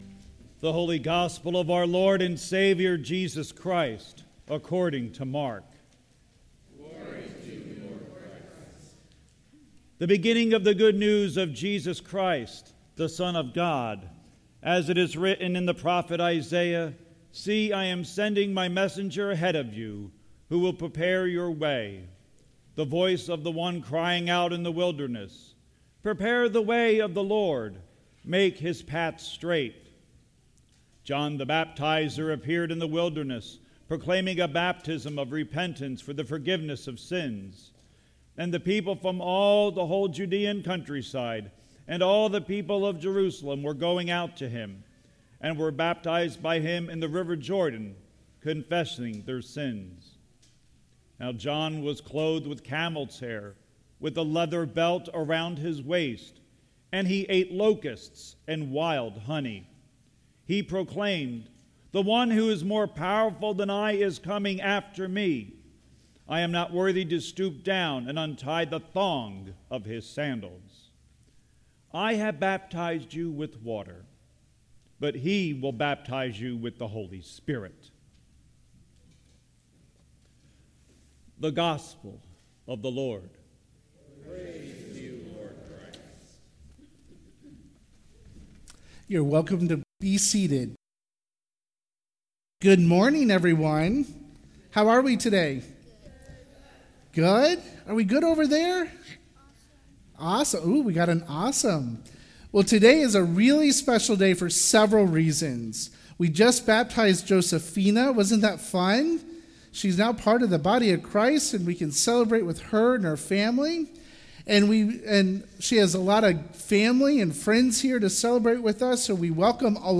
Weekly Gospel & Sermon, December 10, 2017 - St. Andrew's Episcopal Church